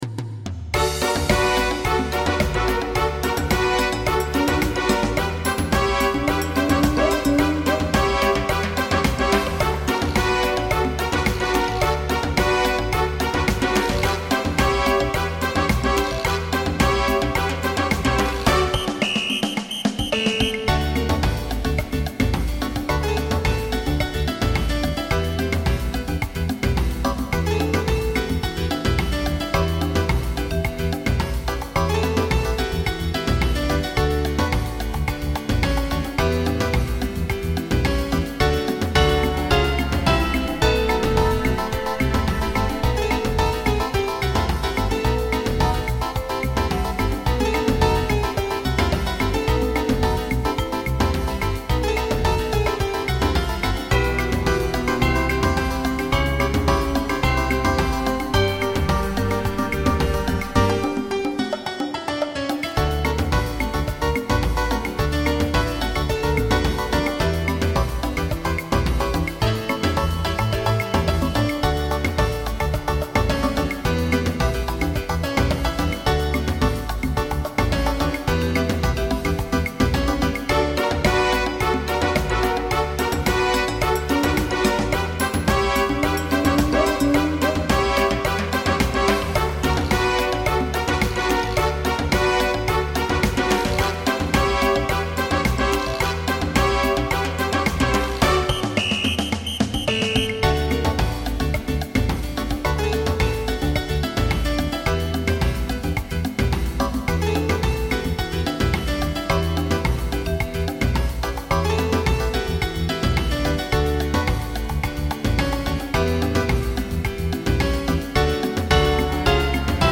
samba